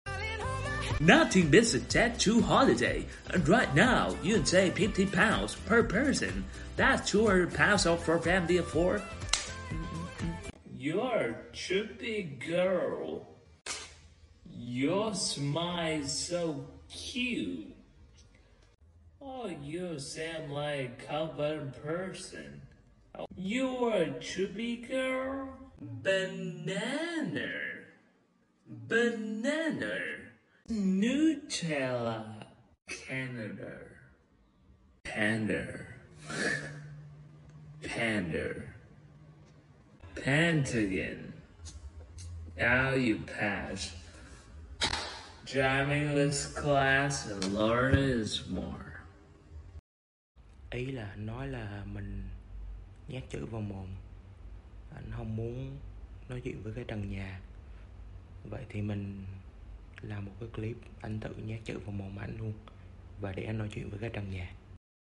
Hay cong lưỡi ở âm /ə/ cuối từ. Đánh trọng âm sai (nutella). Pentagon cũng đọc sai bét.